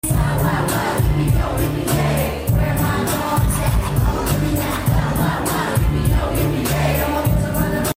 🌴HALVANDET BEACH CLUB in Copenhagen🌴 Save the Date: Sunday, 10th August 2025 Start time: 4pm Summer, sun and 90s & 2000s Hip Hop & RNB!